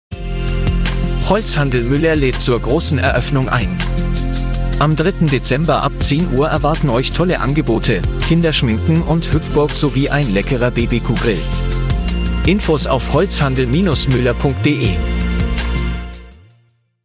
La station de radio allemande Radio Gong, leader en Bavière, a conçu RadioADMaker  une plate-forme qui utilise l’intelligence artificielle et la voix synthétique pour concevoir, rédiger, produire et planifier un spot audio spot, tout cela en trois minutes chrono.
Voici le spot généré par l’IA :
Le résultat est pour l’instant beaucoup moins bon que les messages de synthèse produits aux USA par Adthos ou Aflorithmic (voir notre article récent) qui ont une longueur d’avance sur la qualité des voix, la narration et la production.